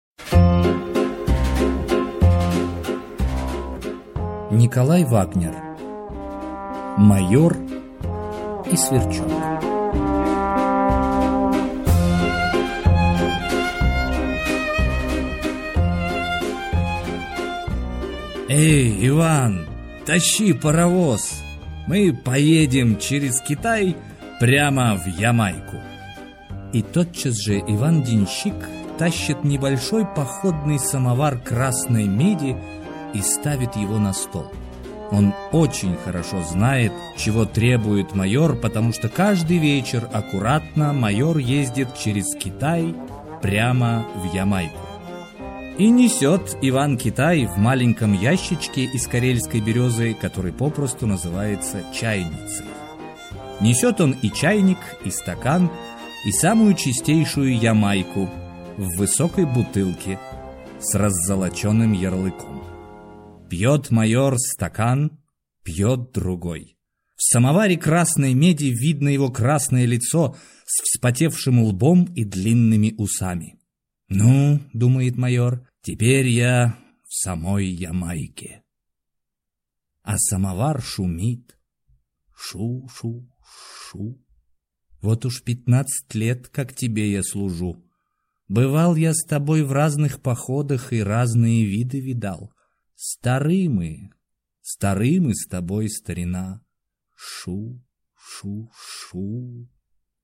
Аудиокнига Майор и сверчок | Библиотека аудиокниг